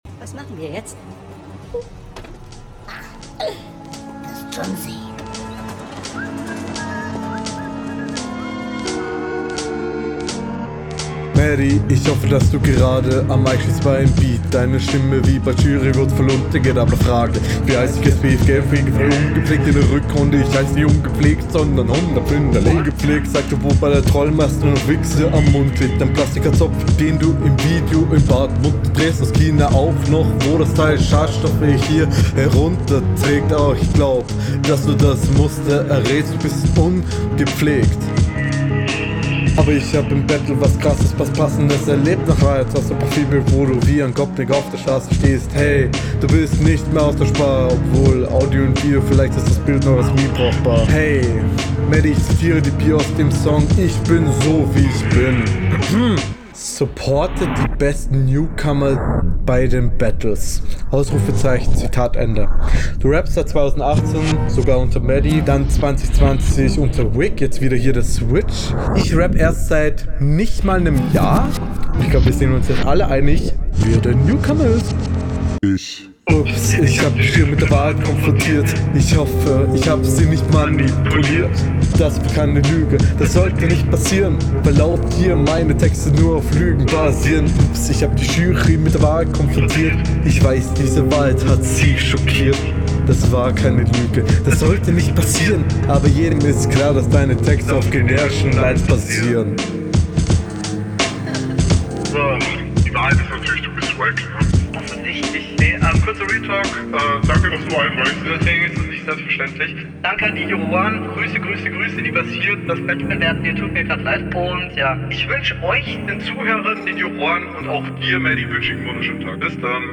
Stimme könnte lauter, geht bisschen im Beat unter, sitzt hier aber wieder besser im beat!
Die Runde ist wirklich schwer zu verstehen.